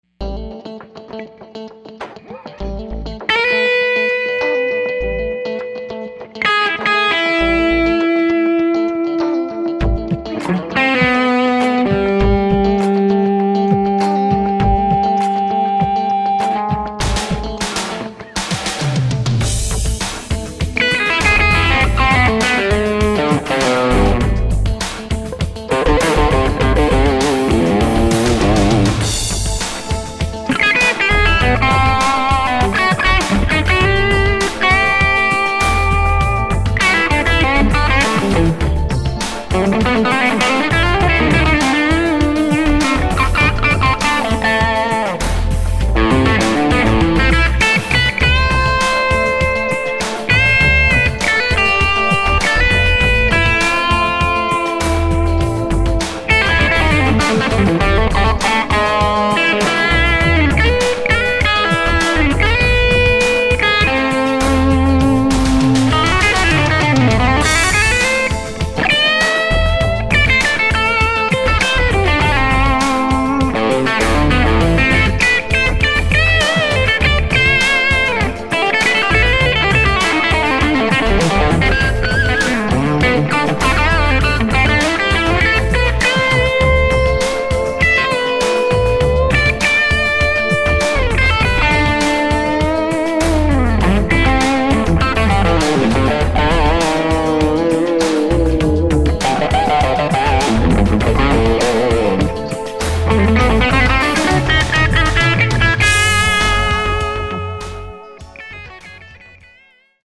Clearly I was just riffing out but I suspect that was my Les Paul Custom on that track. The poor thing has Wolfetone Marshall head pickups (aggressive PAF tone). Only the lead track was TC-15.
I hear some of what I LOVED in the Bangkok clips in this one....but the tone is more raw in this one and more refined in the BK clips.